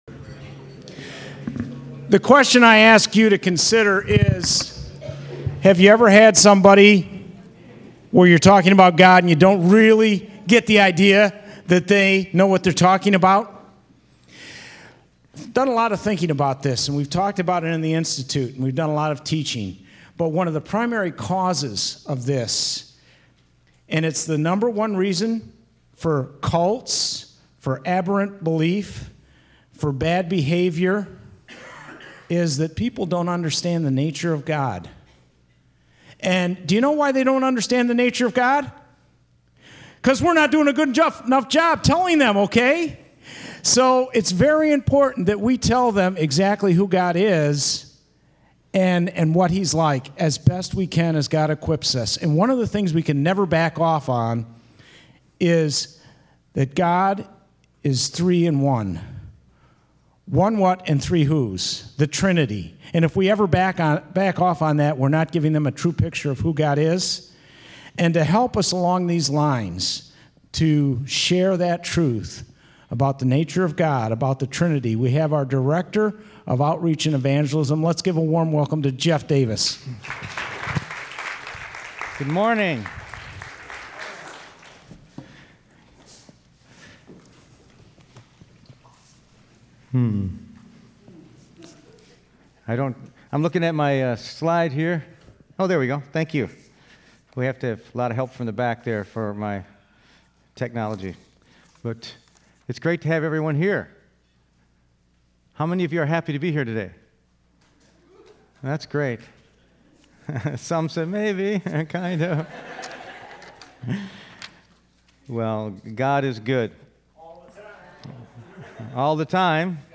John 1:1-14 Service Type: Sunday Morning %todo_render% « Shine the Light